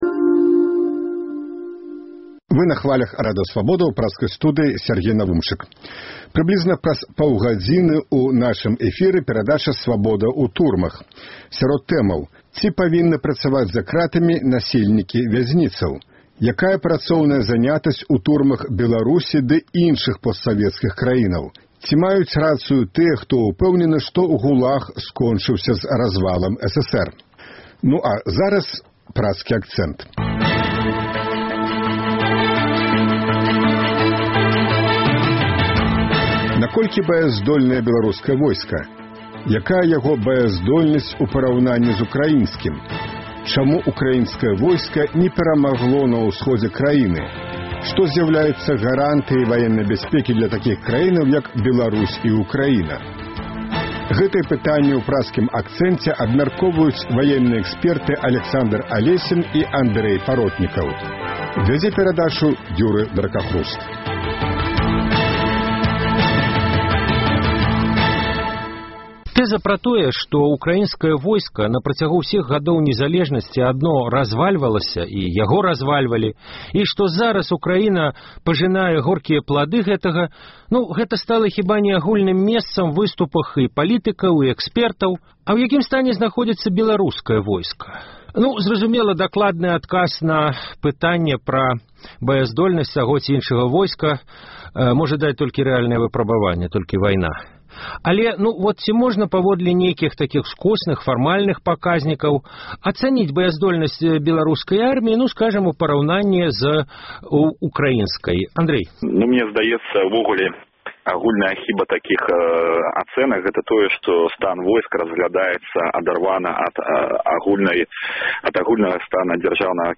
Якая яго баяздольнасьць у параўнаньні з украінскім? Чаму ўкраінскае войска не перамагло на ўсходзе краіны? Што зьяўляецца гарантыяй ваеннай бясьпекі для такіх краін, як Беларусь і Ўкраіна? Гэтыя пытаньні ў Праскім акцэнце абмяркоўваюць ваенныя экспэрты